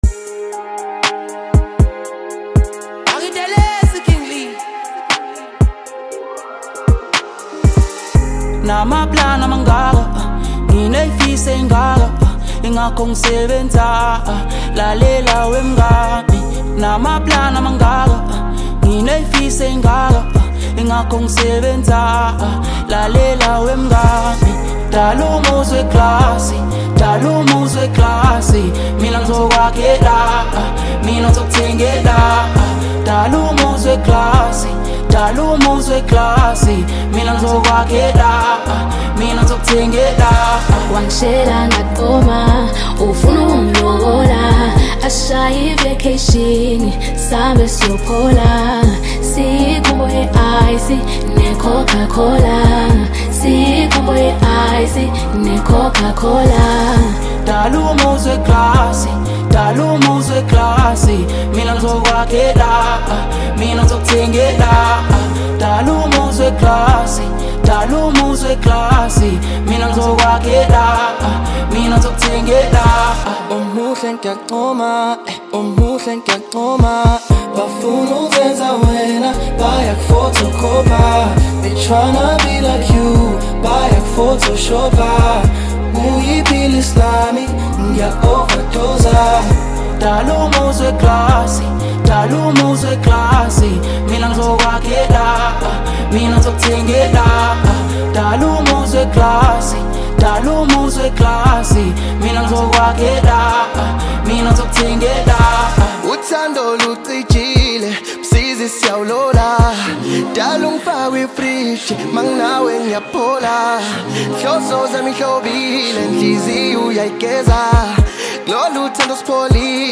Home » Amapiano » Hip Hop